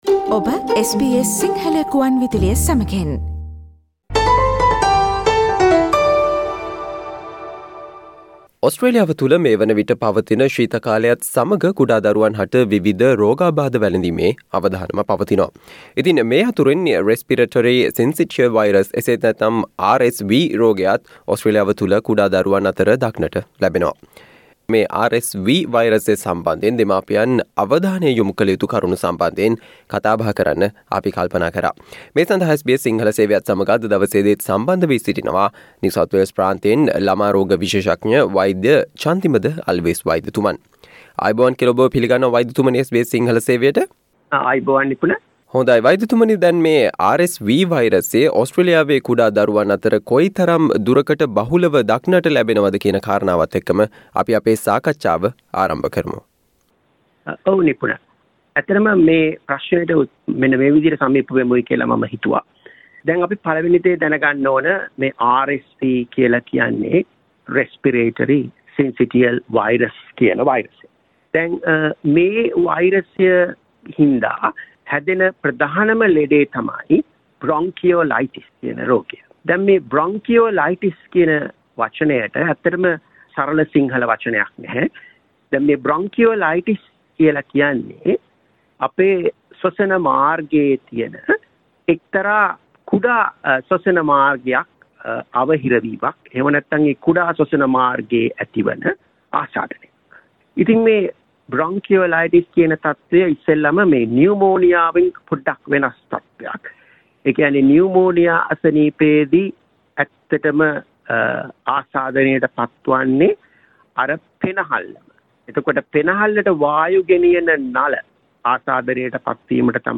ඔස්ට්‍රේලියාවේ ශීත කාලය අතරතුර කුඩා දරුවන්ට වැළඳිය හැකි RSV වෛරසය සම්බන්ධයෙන් දෙමාපියන් අවධානය යොමු කල යුතු කරුණු සම්බන්ධයෙන් SBS සිංහල සේවය සිදු කල සාකච්චාවට ඔබට මේ ඔස්සේ සවන් දිය හැක.